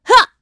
Isolet-Vox_Jump.wav